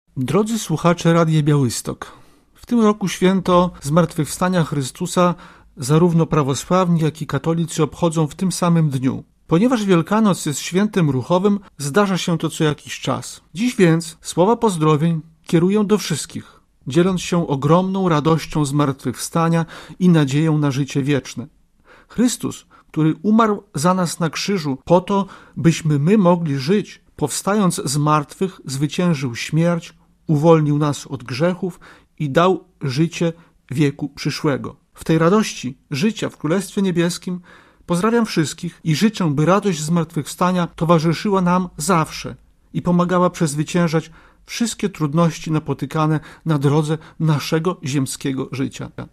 Świąteczne życzenia arcybiskupów